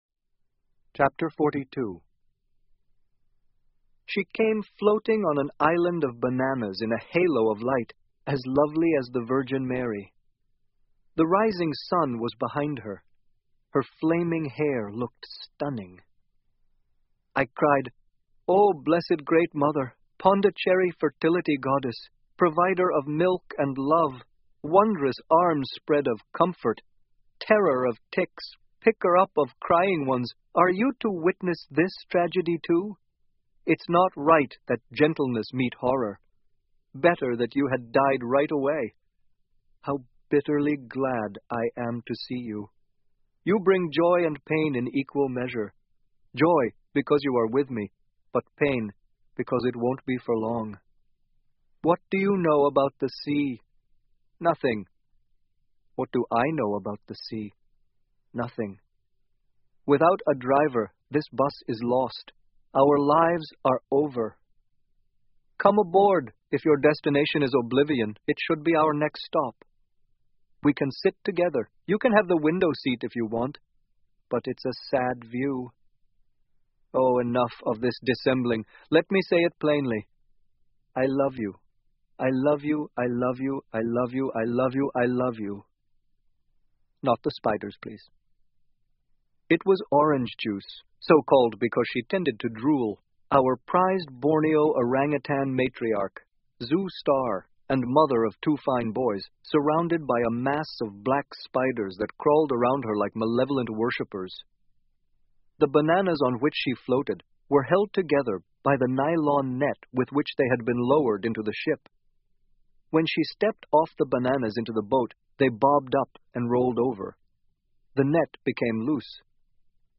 英文广播剧在线听 Life Of Pi 少年Pi的奇幻漂流 04-01 听力文件下载—在线英语听力室